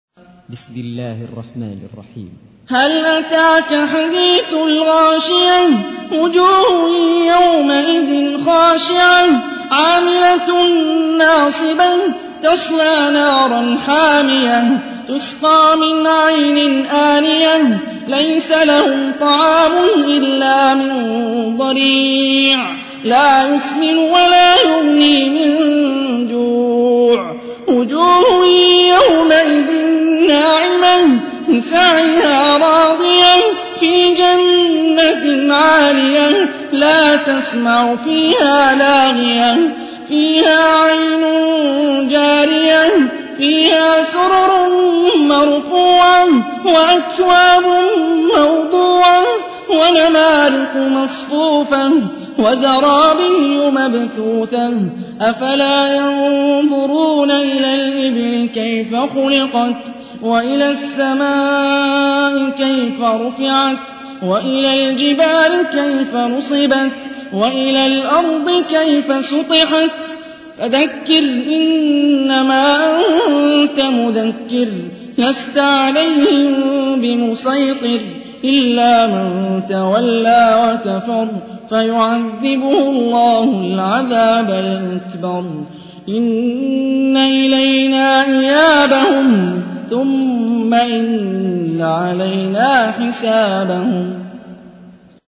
تحميل : 88. سورة الغاشية / القارئ هاني الرفاعي / القرآن الكريم / موقع يا حسين